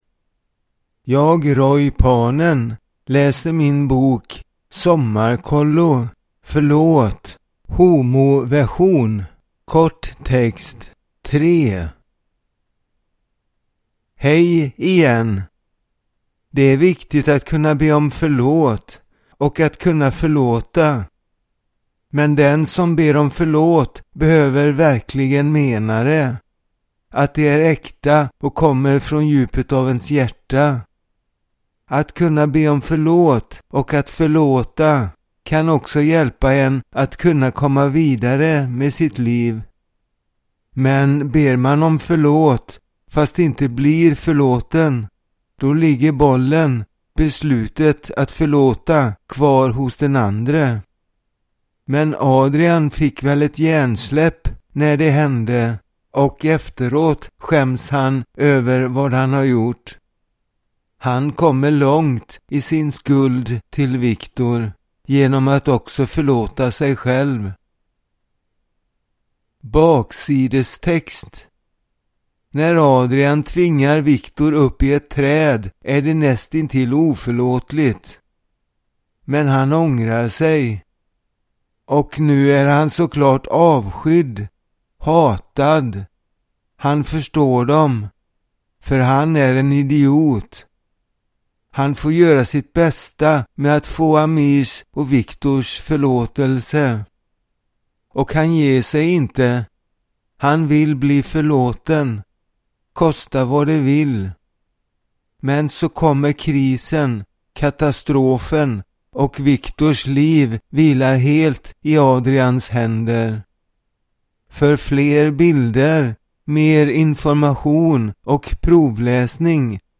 SOMMARKOLLO Förlåt!!! (homoversion) (kort text) – Ljudbok